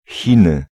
Ääntäminen
Ääntäminen Tuntematon aksentti: IPA: /ˈɕiːˌna/ Haettu sana löytyi näillä lähdekielillä: ruotsi Käännös Ääninäyte Substantiivit 1.